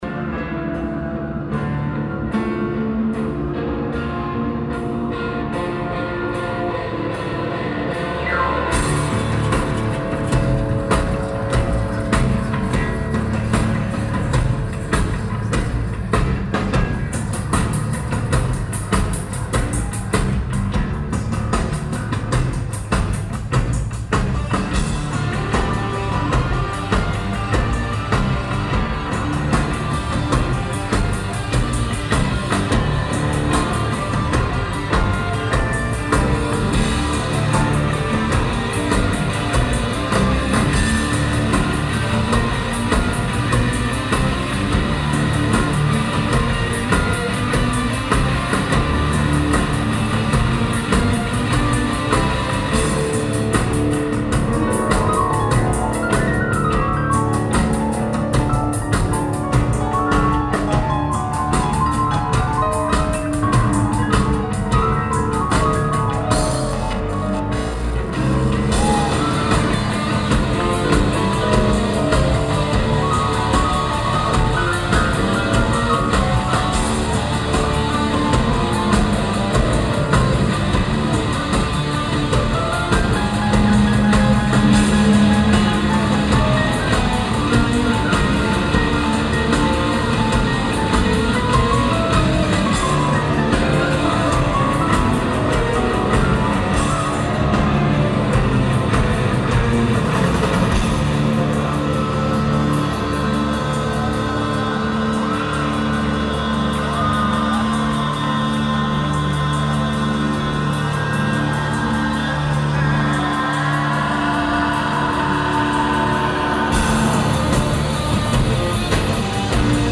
The Palladium
Lineage: Audio - AUD (Roland R05 + Internals)
Notes: Taped from FOH, right stack.